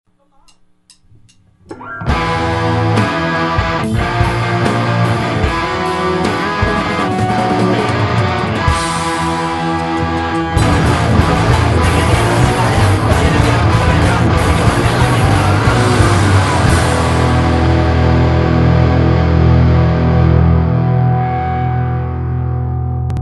They are a very tongue-in-cheack hardcore band.